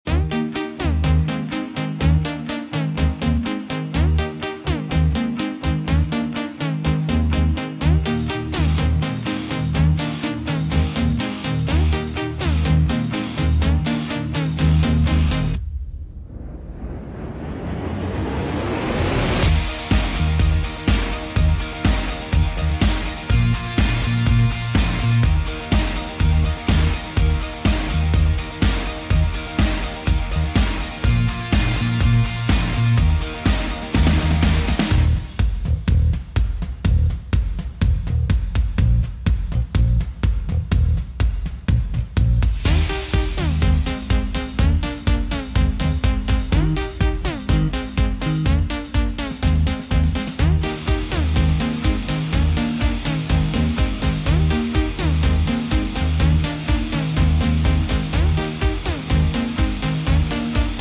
FERRARI 296 GT3 sound effects free download